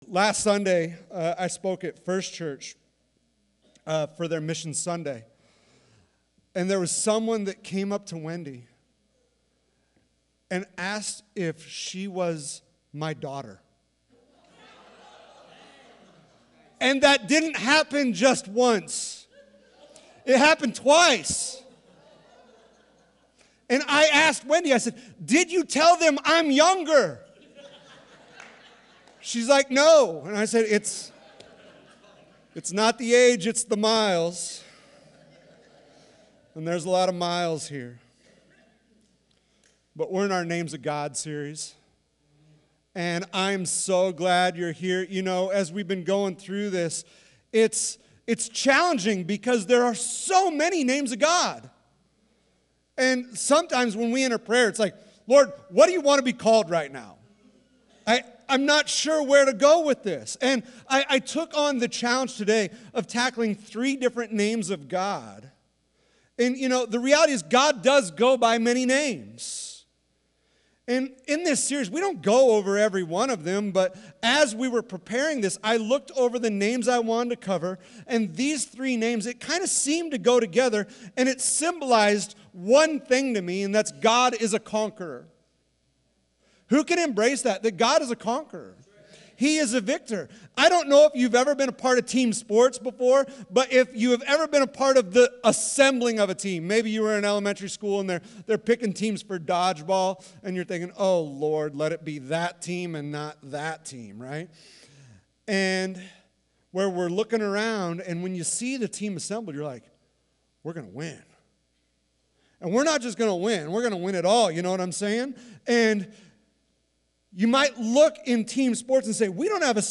Sermons | Sunshine Open Bible Church